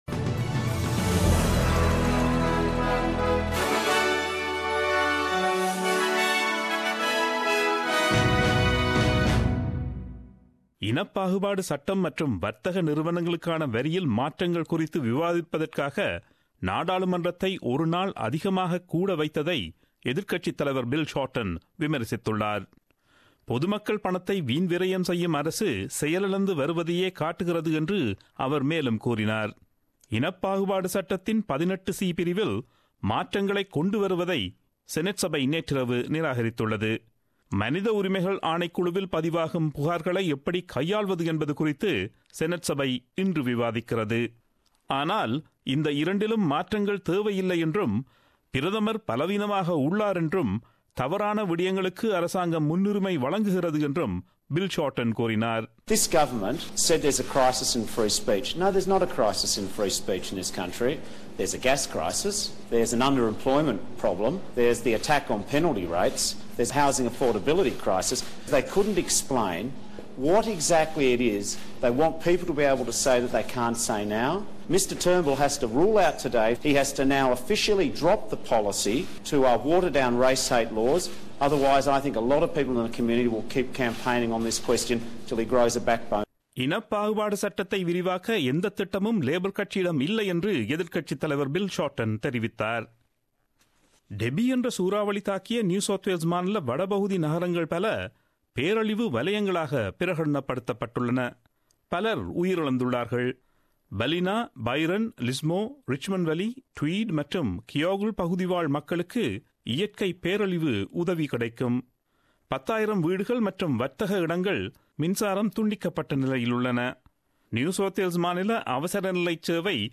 Australian news bulletin aired on Friday 31 Mar 2017 at 8pm.